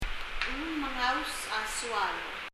発音
mengaus　　　[məŋaus]　　　　編む　　weave